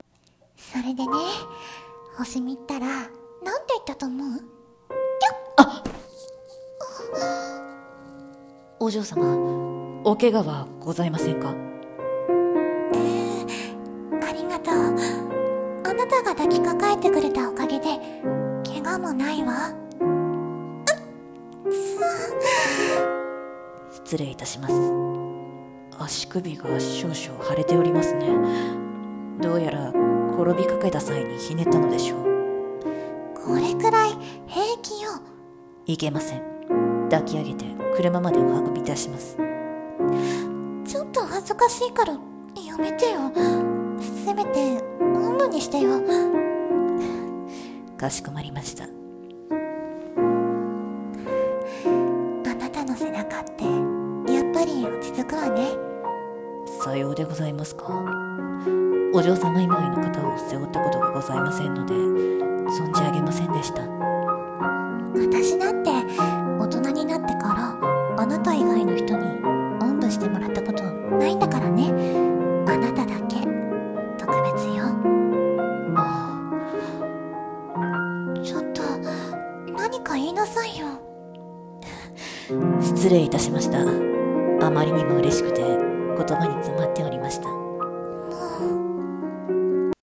声劇【お嬢様と執事 2】